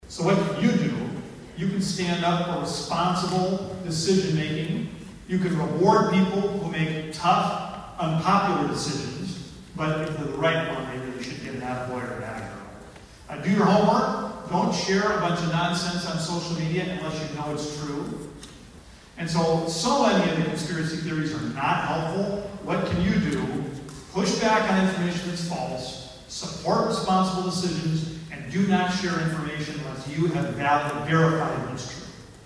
ABERDEEN, S.D.(HubCityRadio)- Monday kicked off the 82nd Boys State taking place at Northern State University in Aberdeen.